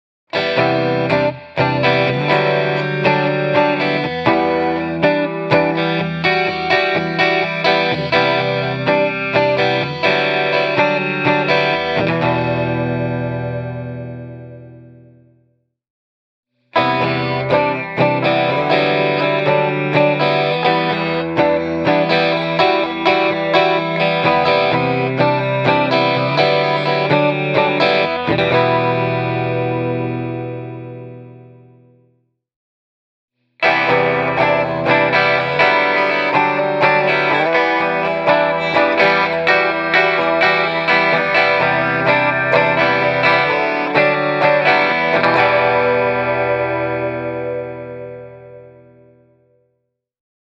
It is possible that the Evertune-bridge adds a little to this model’s weight, but what is very clear to me is the added ring and sustain of the Evertune-equipped LTD.
The guitar stays in tune and rings freely nonetheless.
This EMG-set sounds a little bit more neutral to my ears. It’s maybe a tad dryer than the Andy James-model’s tone – it’s different, but still very good: